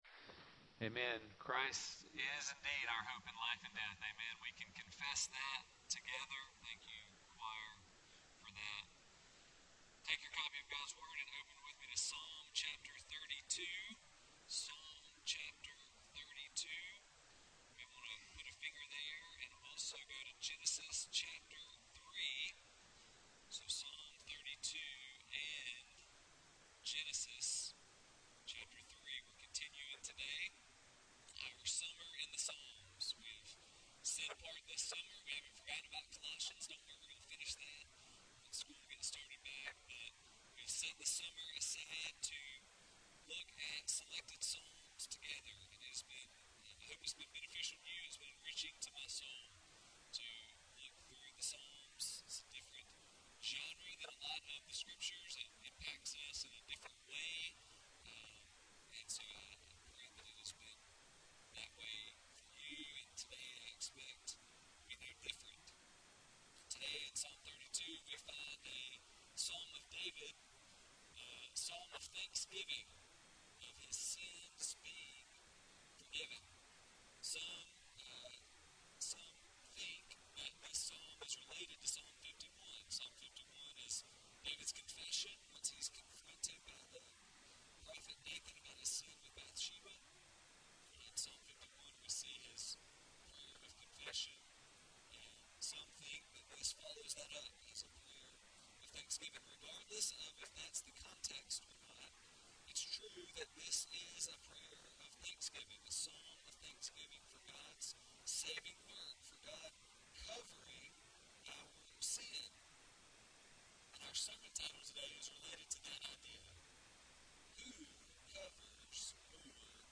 Sermon Series: Summer in Psalms